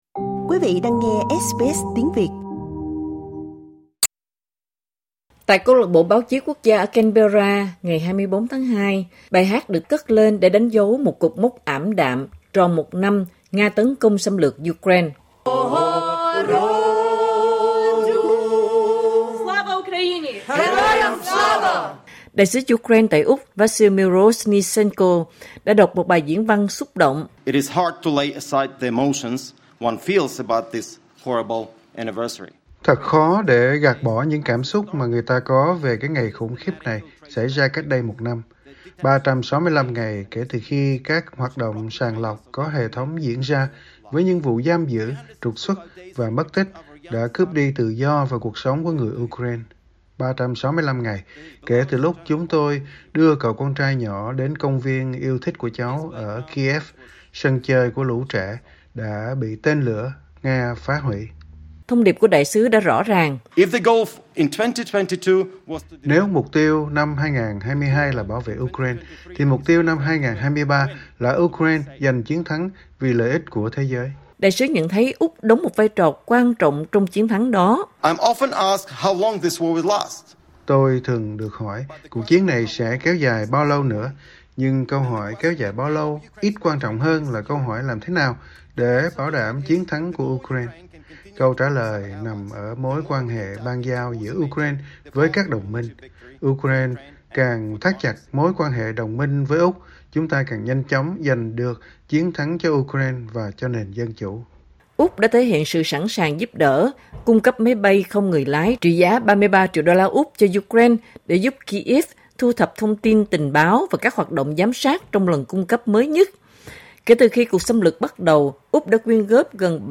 Phát biểu trước Câu lạc bộ Báo chí Quốc gia ở Canberra, Đại sứ Ukraine tại Úc, Vasyl Myroshnychenko nói rằng Ukraine sẽ chiến đấu cho đến khi giành được chiến thắng, trong đó thặt chặt mối quan hệ đồng minh - bao gồm Úc - là điều vô cùng quan trọng để đi đến thắng lợi.